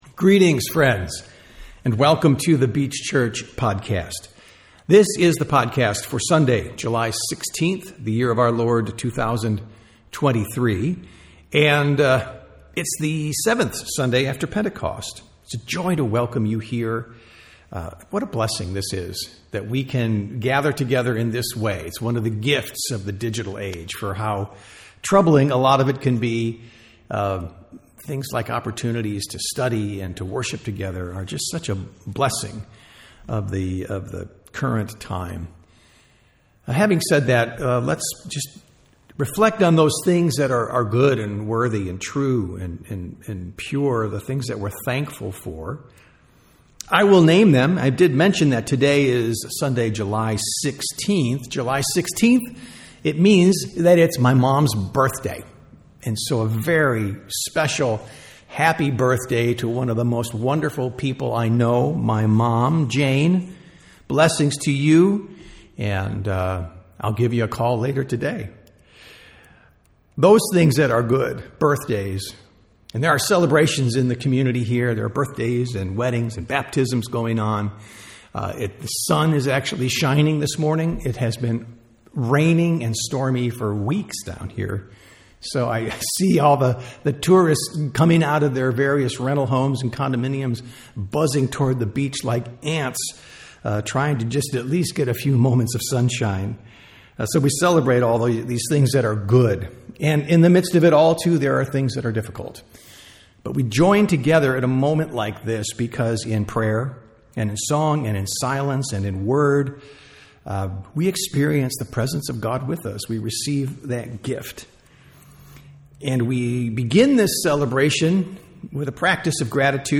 Sunday Worship - July 16, 2023
Sermon Notes